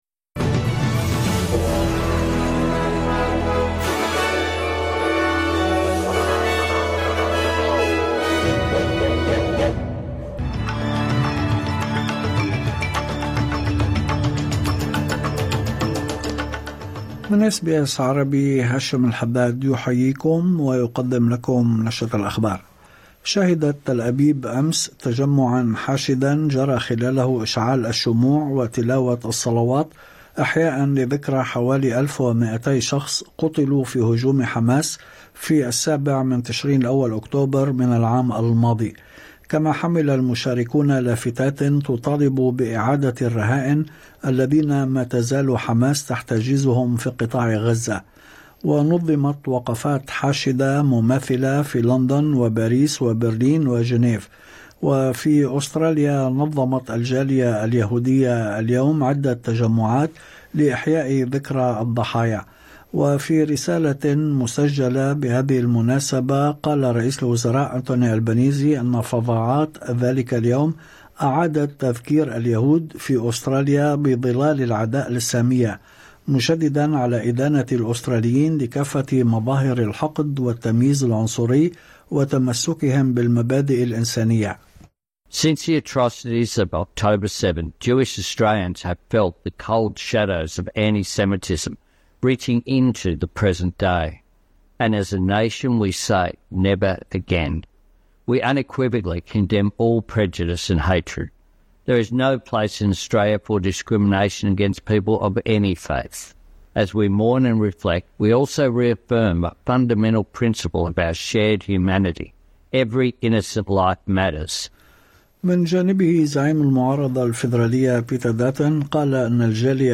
نشرة أخبار الظهيرة 7/10/2024
التفاصيل في النشرة الاخبارية كاملة بالتسجيل الصوتي أعلاه.